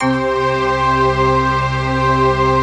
DM PAD5-4.wav